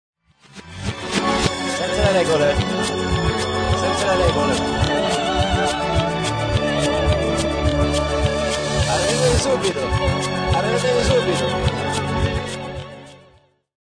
pop rock
Tipo di backmasking Rovesciato